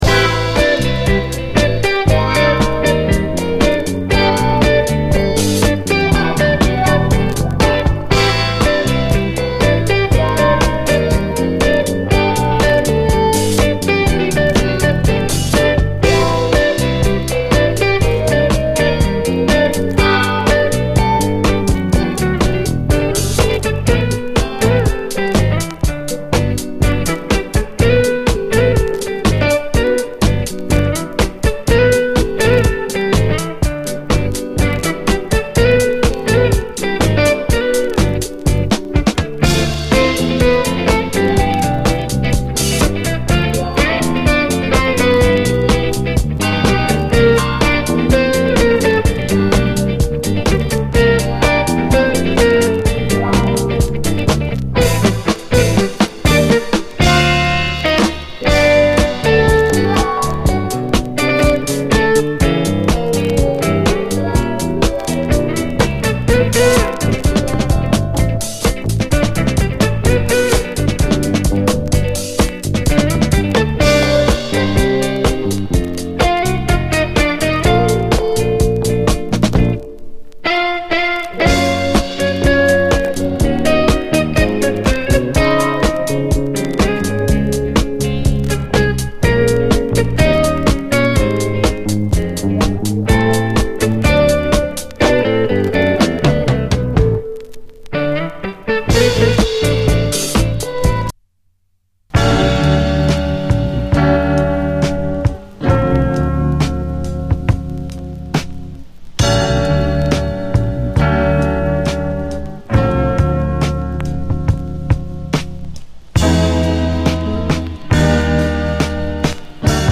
SOUL, 70's～ SOUL
漆黒の南国の夜、といった趣のマイアミ・ソウル名盤！
B面にやや盤そりありますが、PLAY GOOD。
全編インストで、どことなく香る南国フレーヴァーをまとった哀愁のギター・プレイを存分に堪能できます。